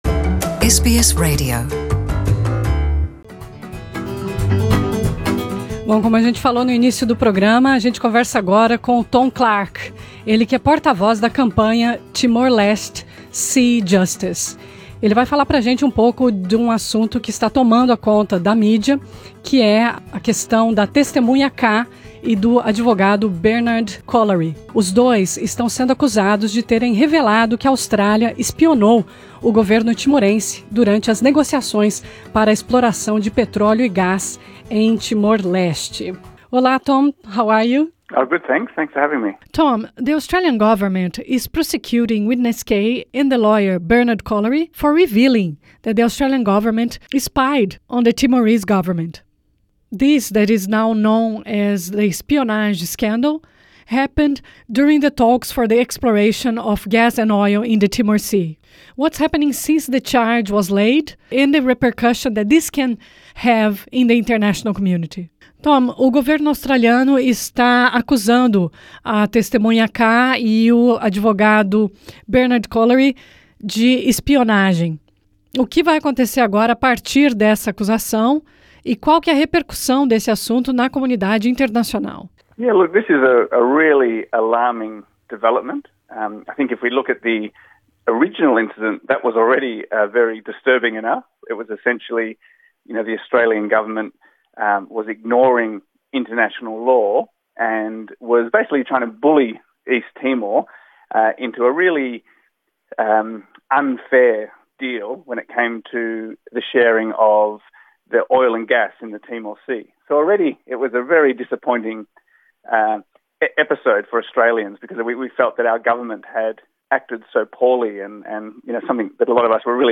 A entrevista é em inglês.